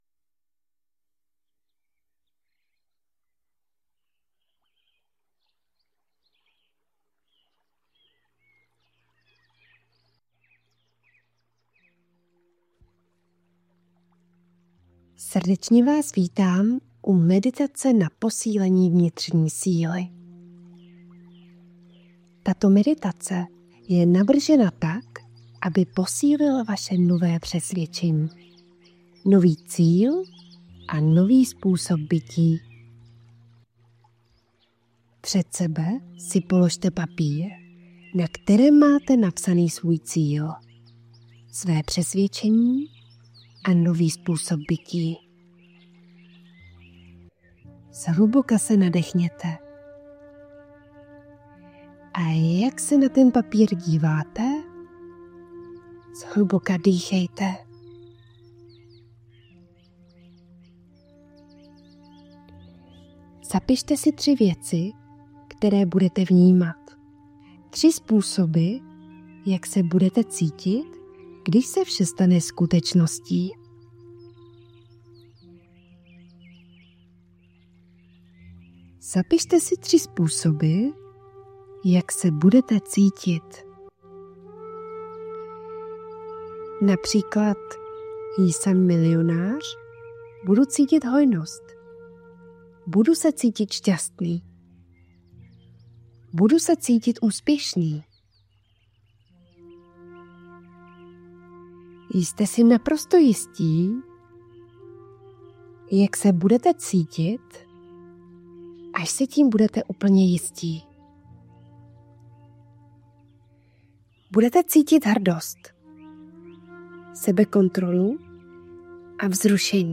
NOVA VERZE - Meditace Vnitřní síla